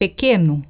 Click the button below to hear the pronunciation of the word